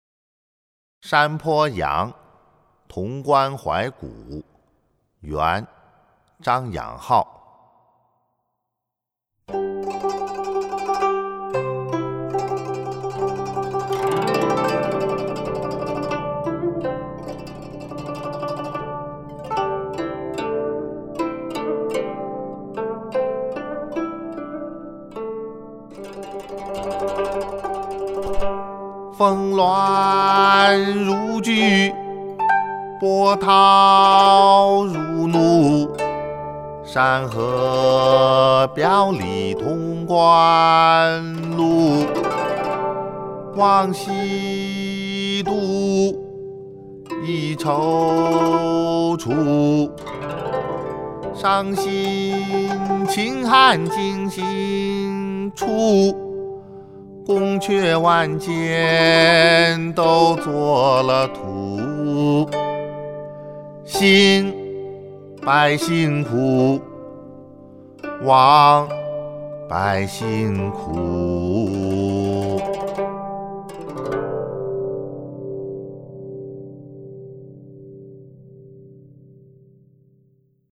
［元］张养浩《山坡羊·潼关怀古》（吟咏）